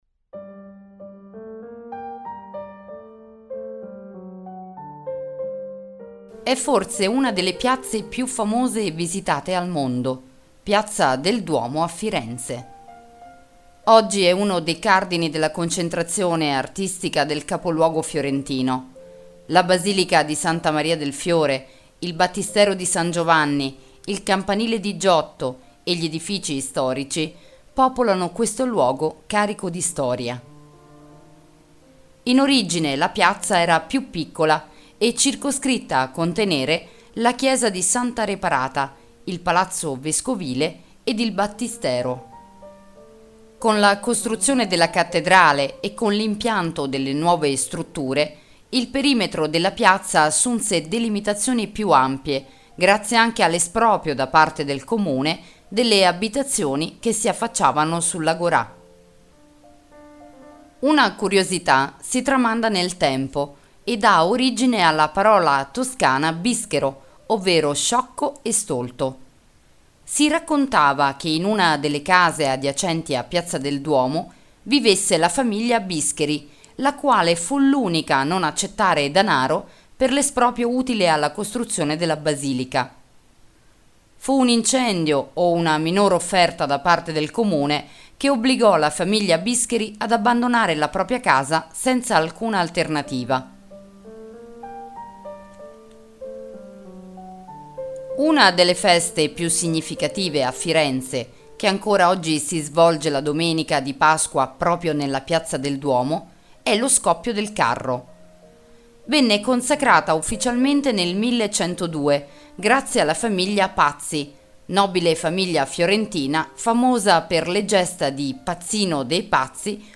Audioguida Firenze – Piazza del Duomo